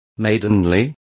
Complete with pronunciation of the translation of maidenly.